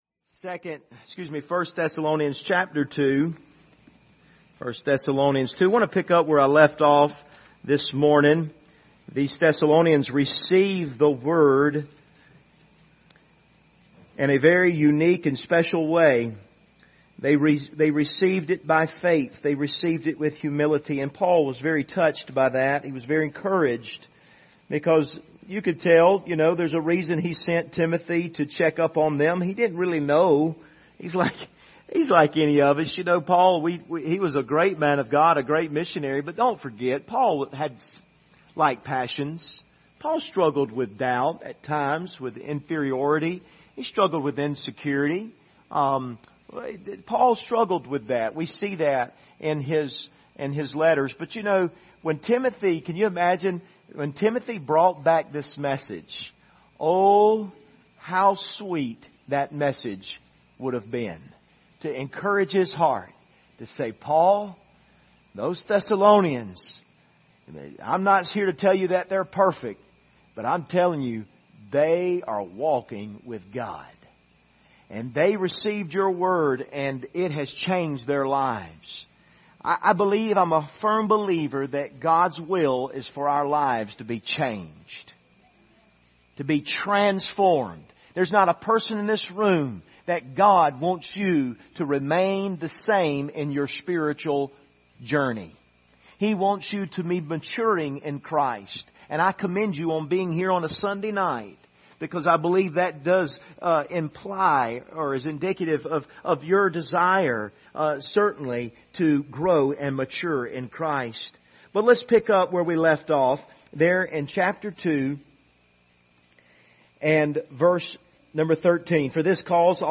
Passage: 1 Thessalonians 2:13-16 Service Type: Sunday Evening